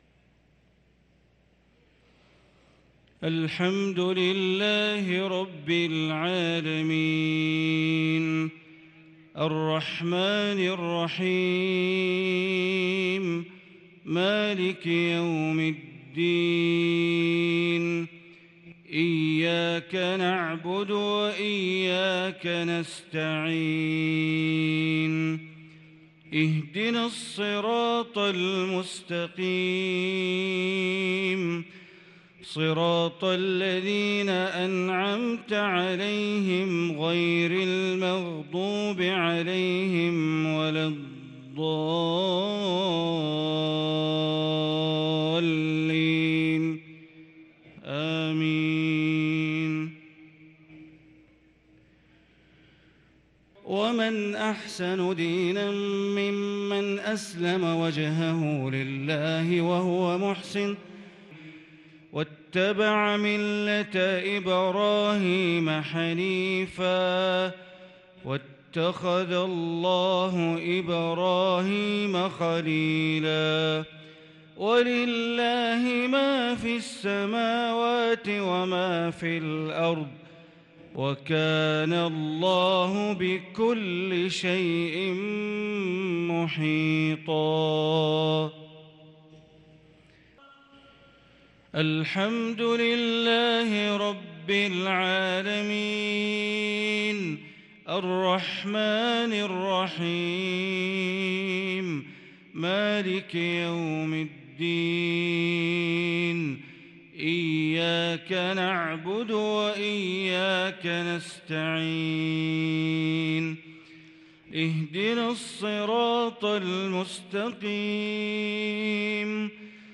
صلاة العشاء للقارئ بندر بليلة 17 ذو الحجة 1443 هـ
تِلَاوَات الْحَرَمَيْن .